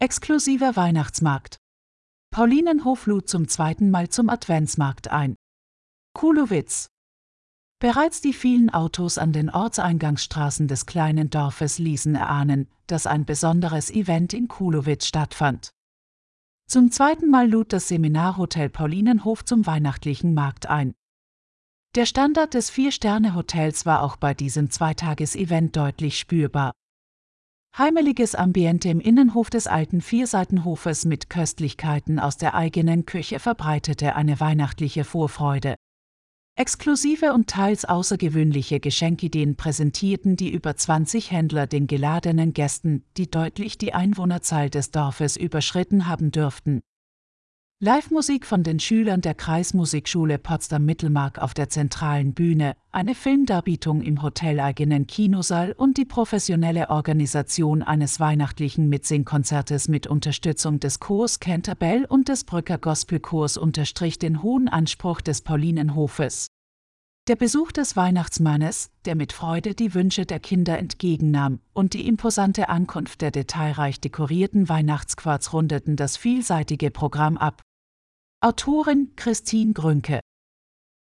Diesen Text kannst du dir auch anhören. Dazu nutzten wir eine Künstliche Intelligenz.